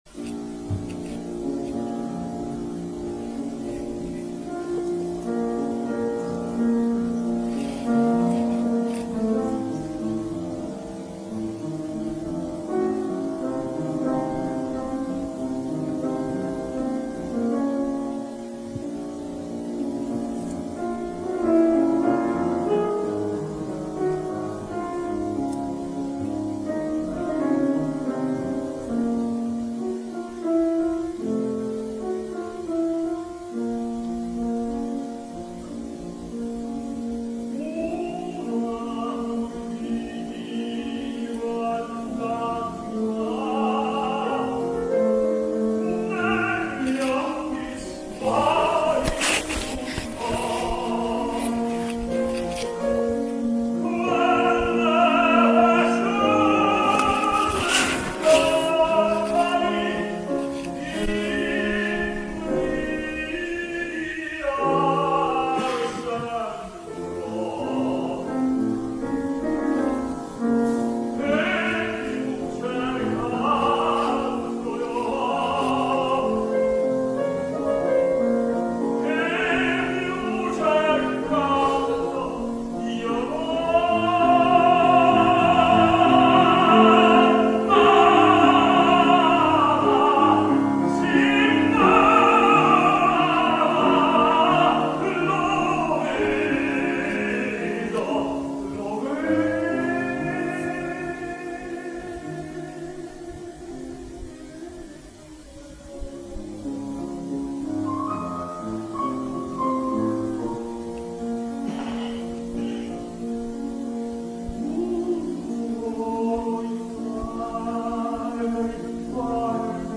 Liederabend
Nicolai Gedda, Tenor
Klavier